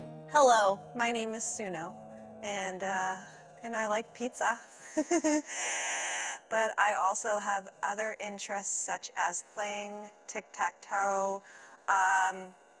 music-generation sound-effect-generation text-to-speech
🔊 Text-Prompted Generative Audio Model Topics Resources
text_prompt Type: stringDefault: Hello, my name is Suno. And, uh — and I like pizza. [laughs] But I also have other interests such as playing tic tac toe.